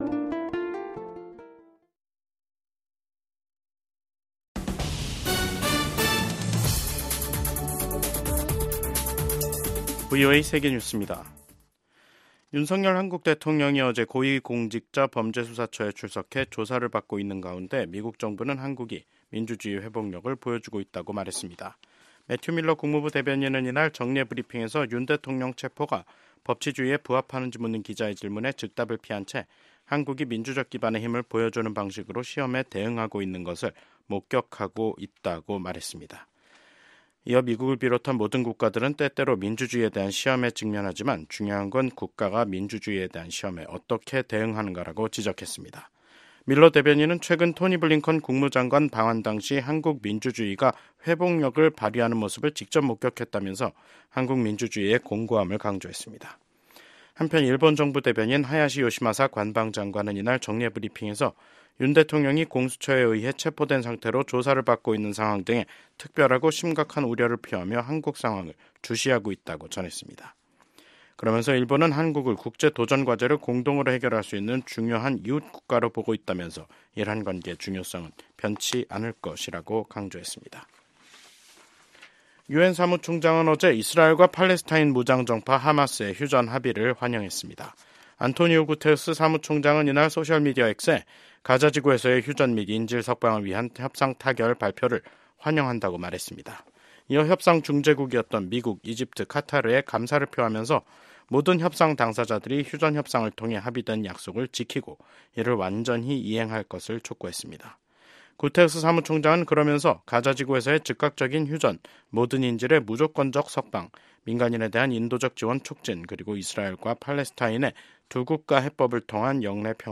VOA 한국어 간판 뉴스 프로그램 '뉴스 투데이', 2025년 1월 16일 2부 방송입니다. 조 바이든 미 행정부와 윤석열 한국 정부가 핵협의그룹(NCG) 출범 등으로 강화시킨 미한 확장억제가 도널드 트럼프 새 행정부 아래에서도 그 기조가 유지될 지 주목됩니다. 미국 국무부는 한국이 정치적 혼란 속에서 민주주의의 공고함과 회복력을 보여주고 있다고 말했습니다.